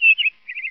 Add canary sounds
sounds_canary_02.ogg